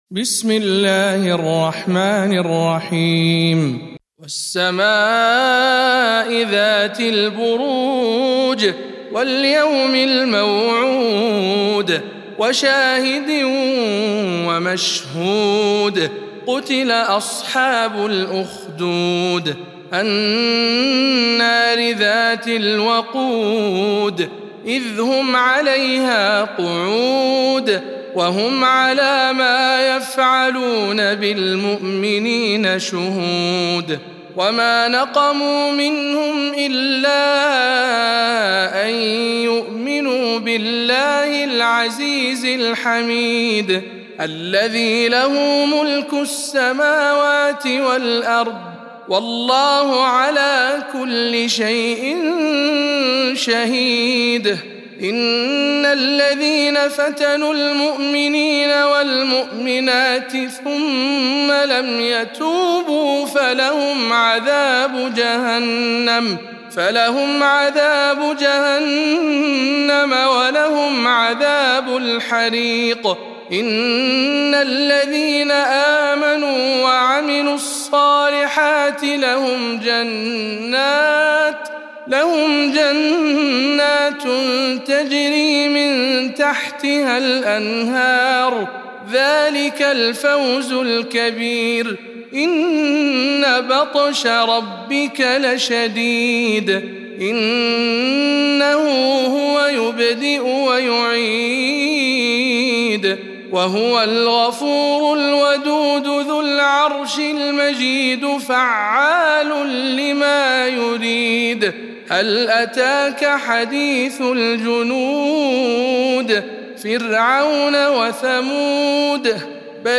سورة البروج - رواية ابن ذكوان عن ابن عامر